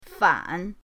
fan3.mp3